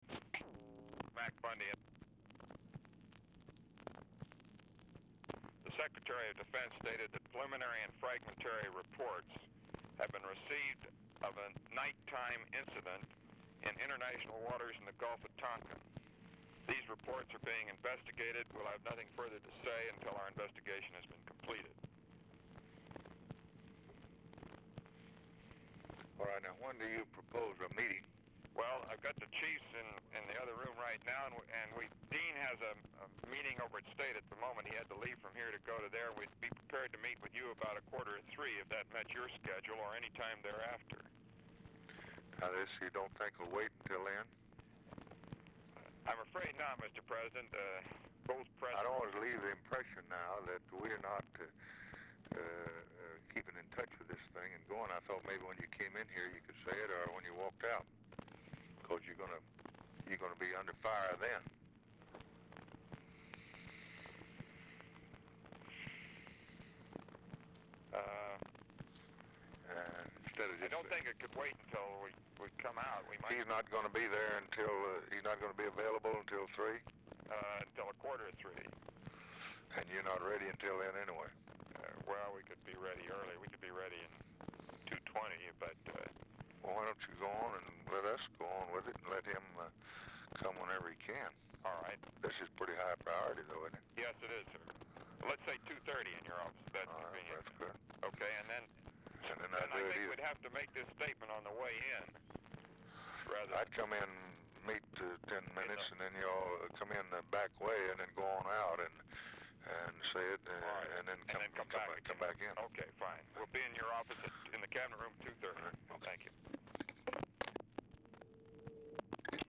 Telephone conversation # 5597, sound recording, LBJ and ROBERT MCNAMARA, 9/18/1964, 1:55PM | Discover LBJ
RECORDING STARTS AFTER CONVERSATION HAS BEGUN
Format Dictation belt
Location Of Speaker 1 Oval Office or unknown location